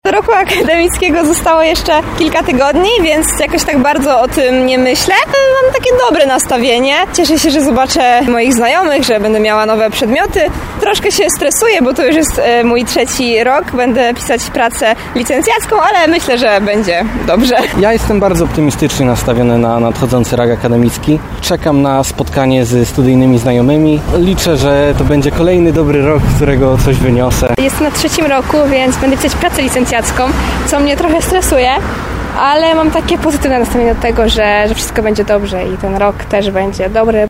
Zapytaliśmy studentów Akademii Tarnowskiej, z jakim nastawieniem do niego podchodzą.